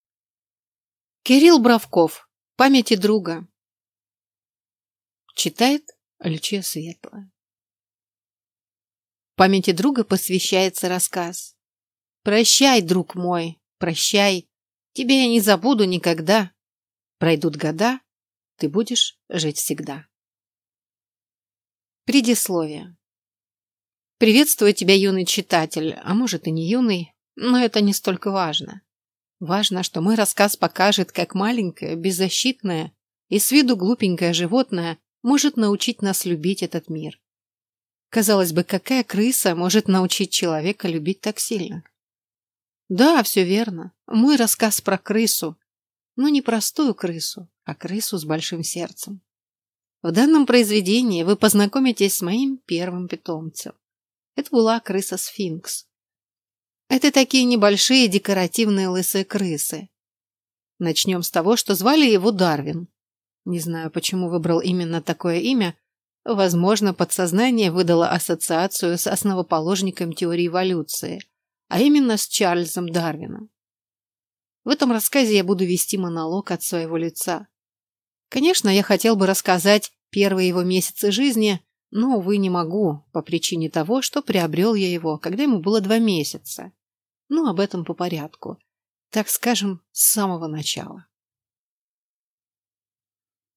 Аудиокнига Памяти друга | Библиотека аудиокниг